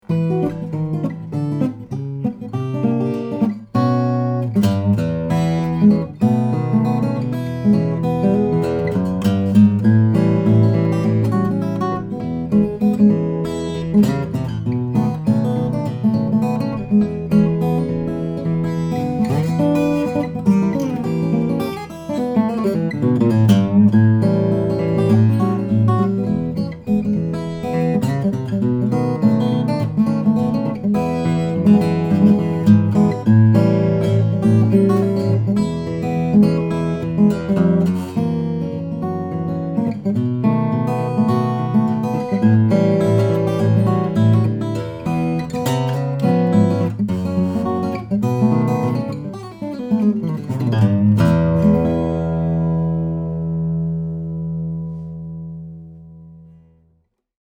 This all mahogany 000 is a gem, powerful, throaty and woody and it plays beautifully.